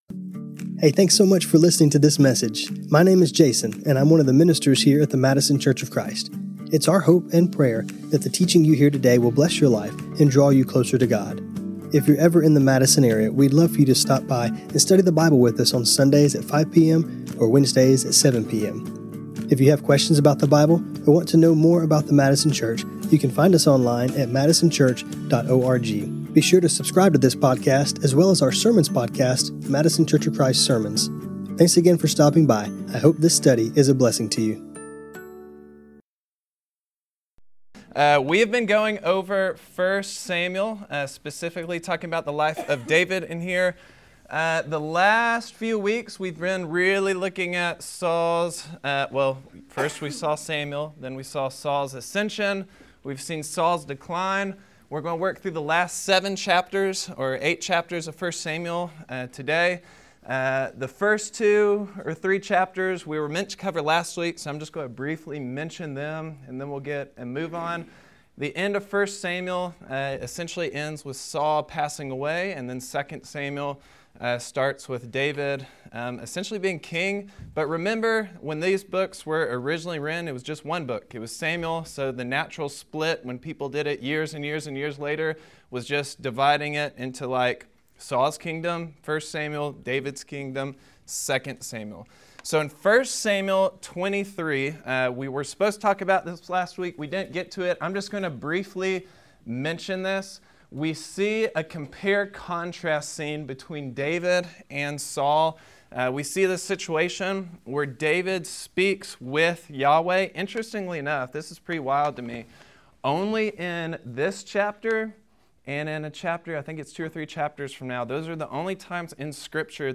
This class was recorded on Dec 10, 2025